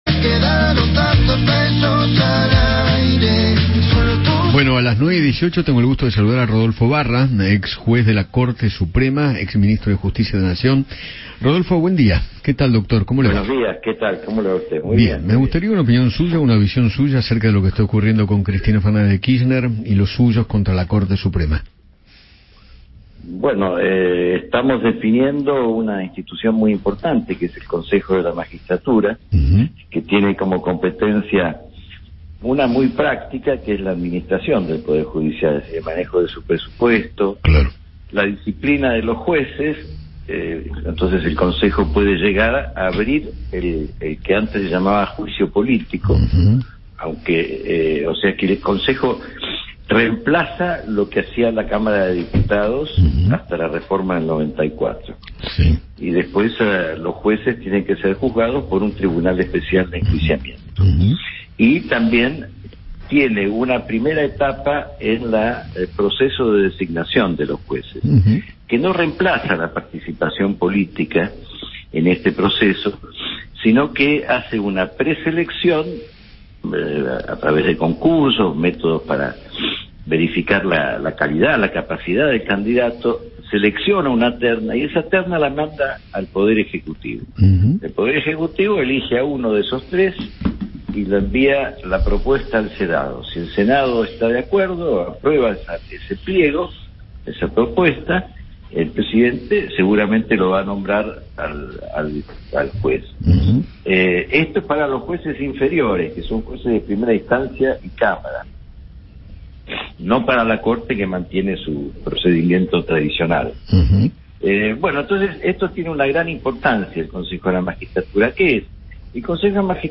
Rodolfo Barra, ex juez de la Corte Suprema, dialogó con Eduardo Feinmann sobre la decisión que tomó el oficialismo de partir el bloque para conseguir una minoría en el recinto.